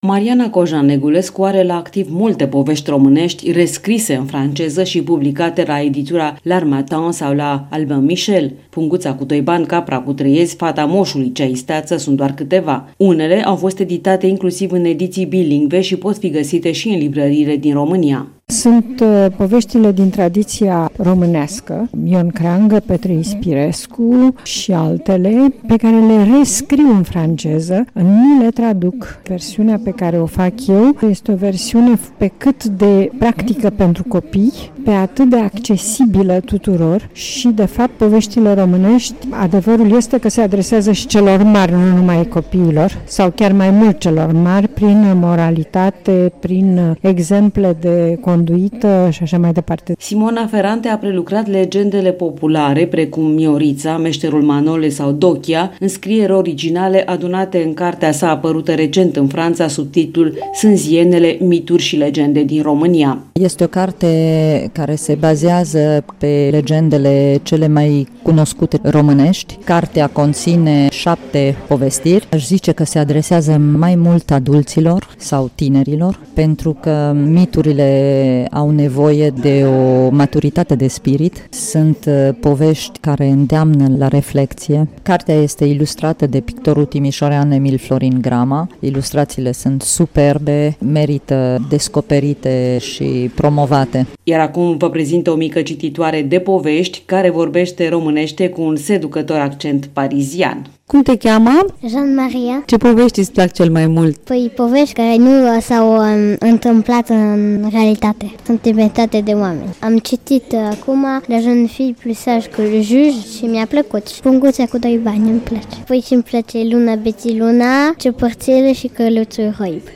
Săptămâna l’Harmattan (interviu la Radio România)